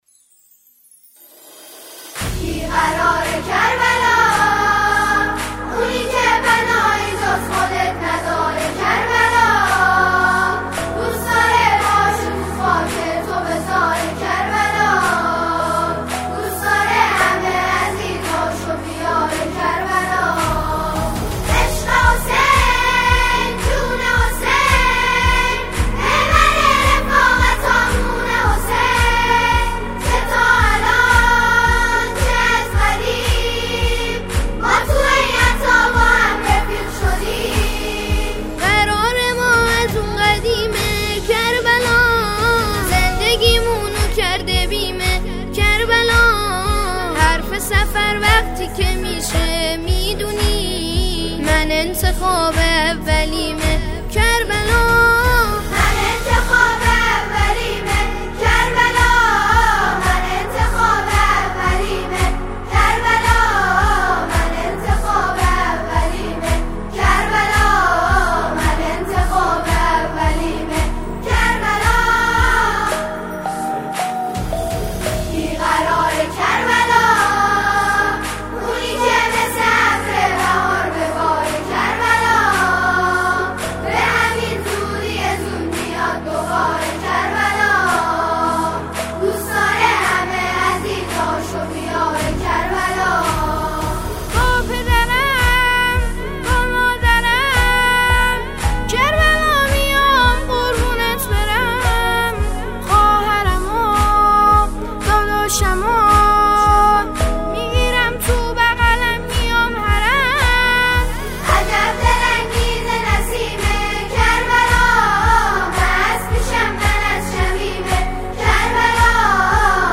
گروه سرود